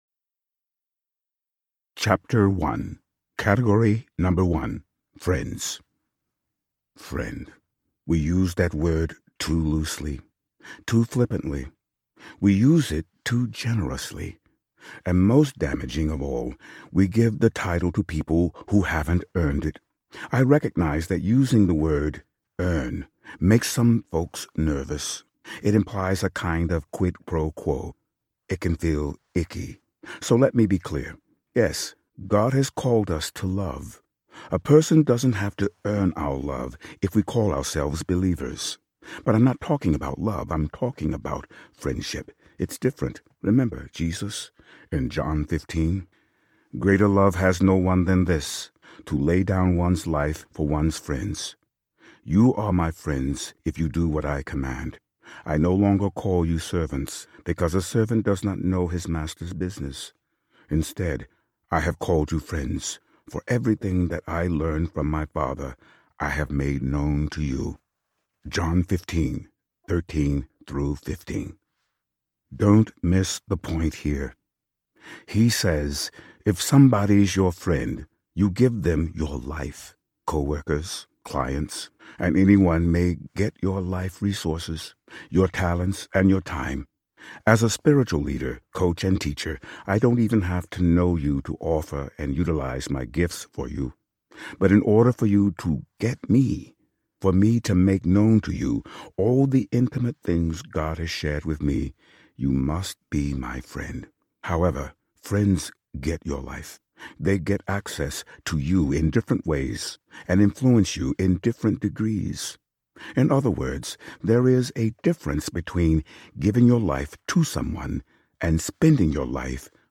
Relational Intelligence Audiobook
Narrator